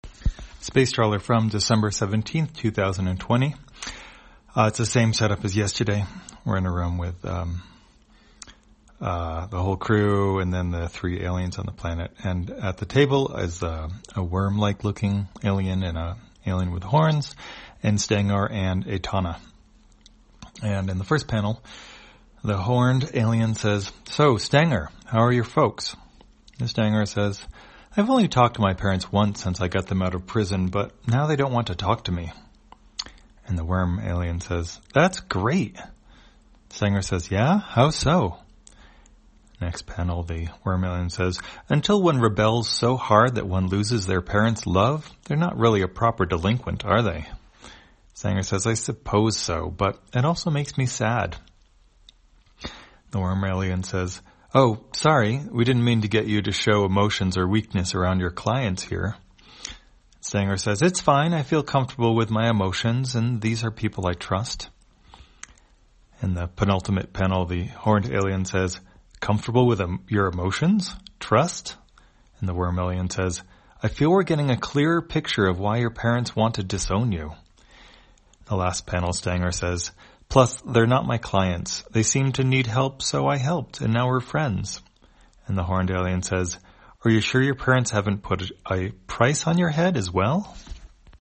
Spacetrawler, audio version For the blind or visually impaired, December 17, 2020.